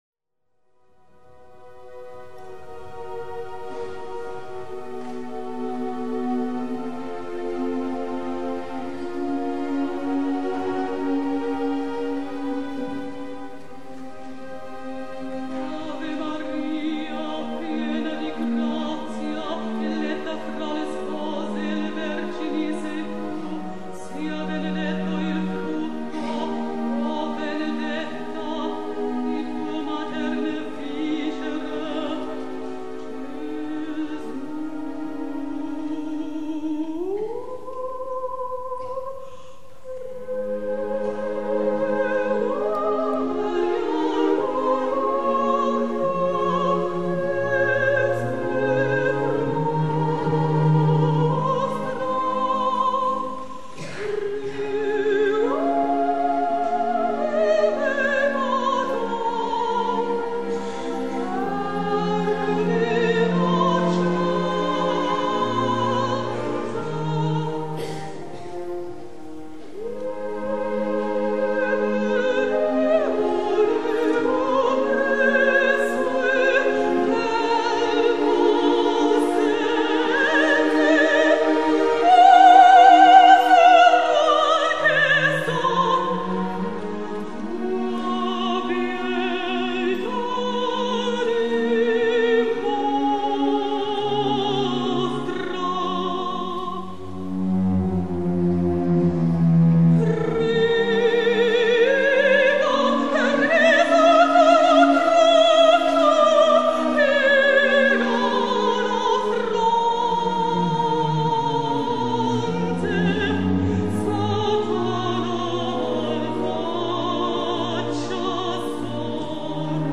Galakonzert München
Chorgemeinschaft Fürstenfeldbruck
Kammerphilharmonie Budweis